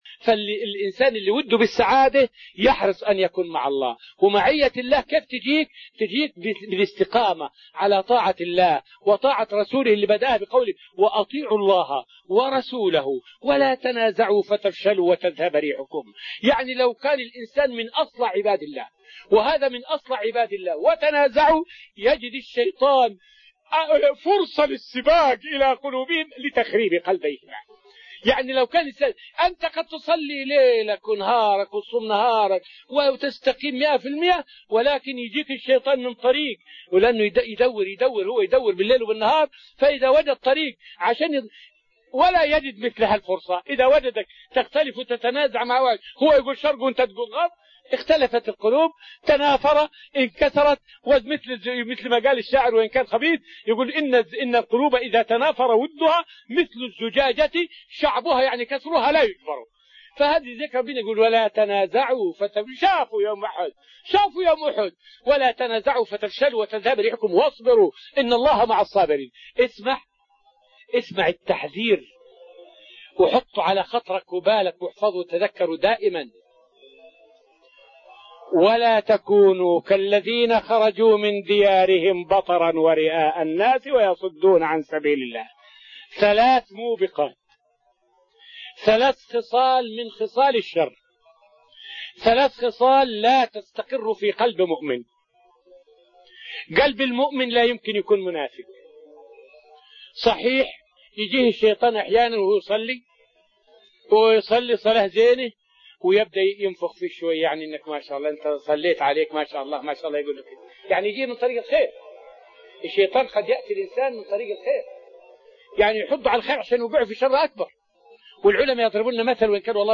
فائدة من الدرس الحادي عشر من دروس تفسير سورة الأنفال والتي ألقيت في رحاب المسجد النبوي حول استدارج الشيطان للإنسان من طرق الخير.